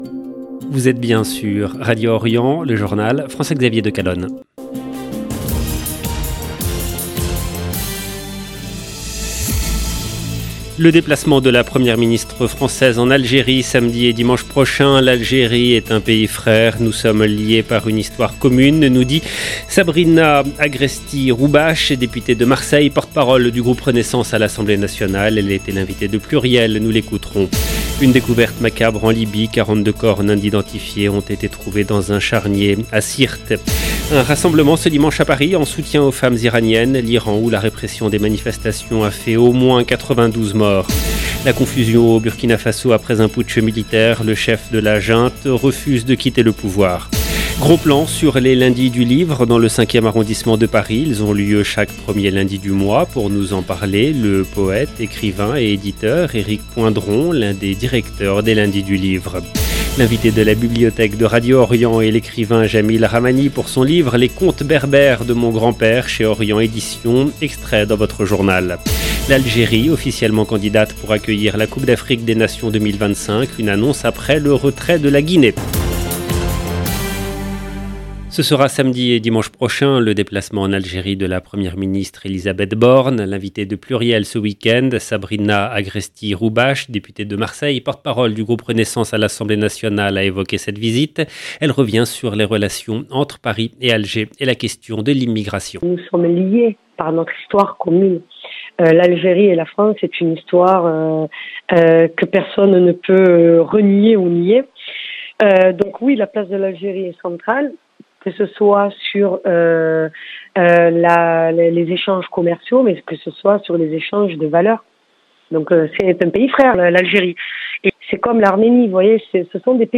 EDITION DU JOURNAL DU SOIR EN LANGUE FRANCAISE DU 2/10/2022